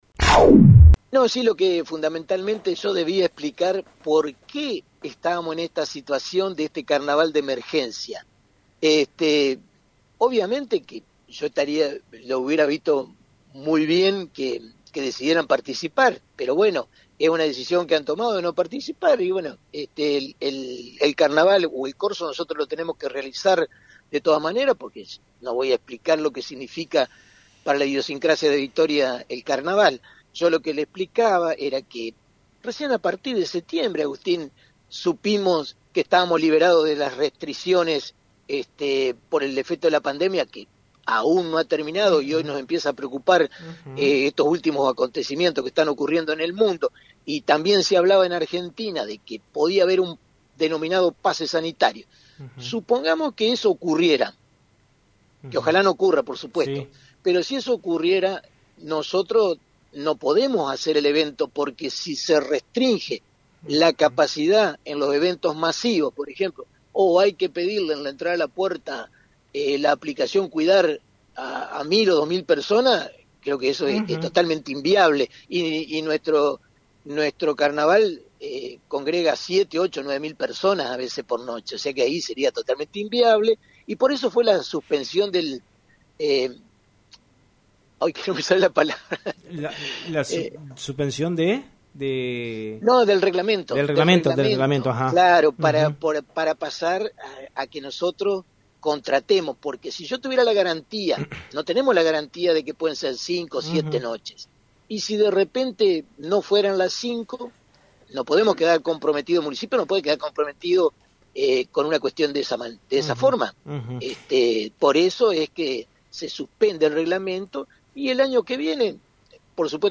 En diálogo con FM 90.3, el intendente Domingo Maiocco confirmó que mañana, en conferencia de prensa, se informarán de los detalles de la edición 2022 de la fiesta mayor de la ciudad.